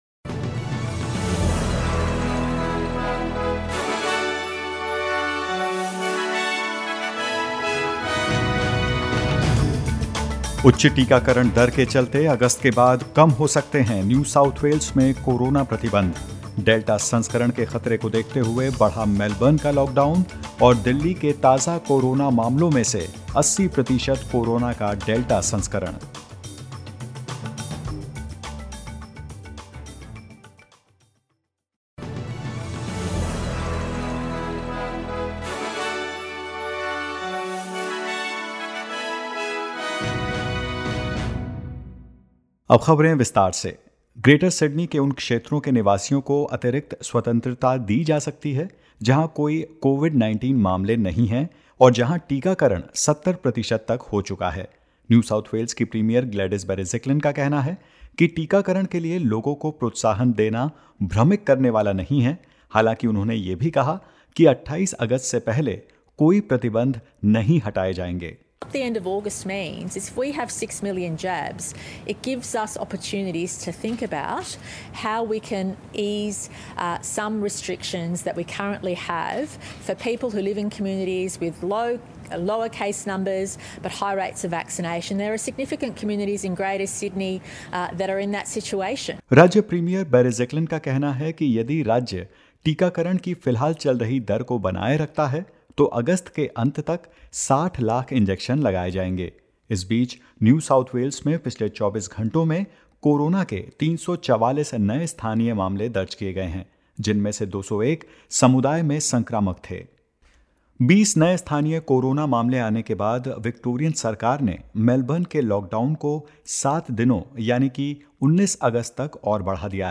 In this latest SBS Hindi News bulletin of Australia and India: Queensland records four new locally acquired cases linked to known outbreaks; NSW records 344 new locally acquired cases, with at least 65 in the community while infectious and more